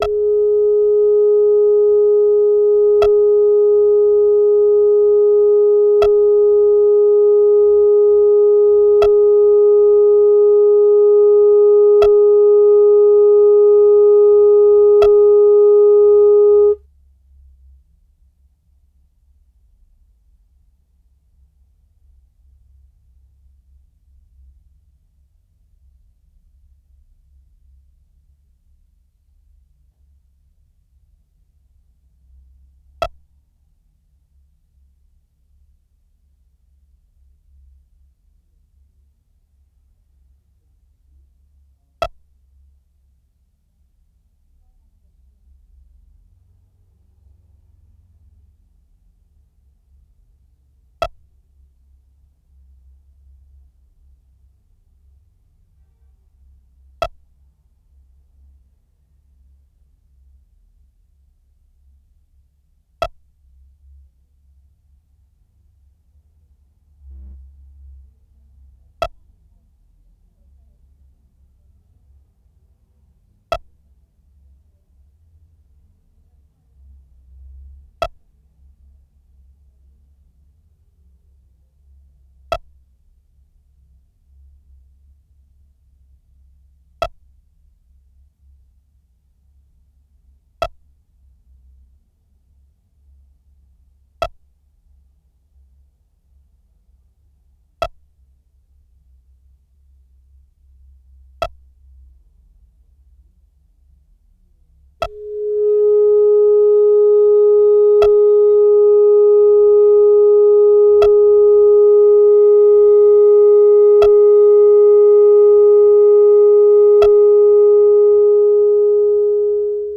Seven tracks, all exactly seven minutes long.